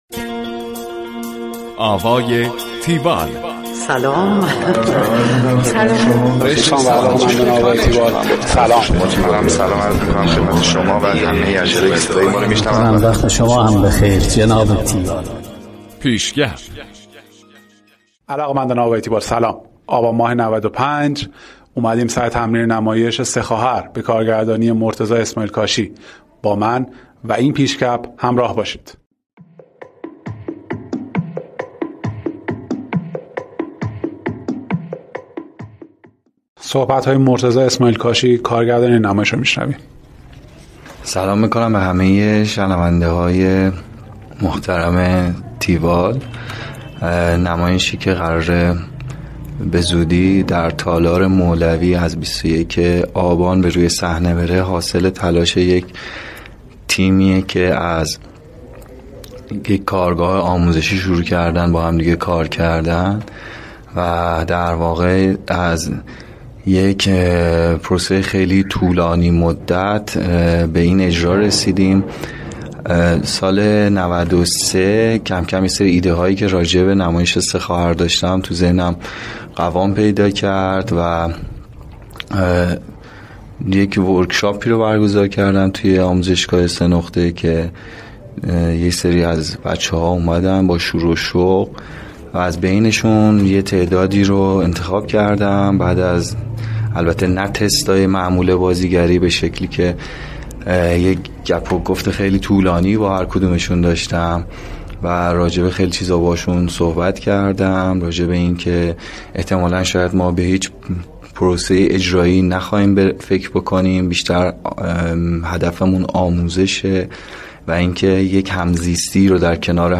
گزارش آوای تیوال از نمایش سه خواهر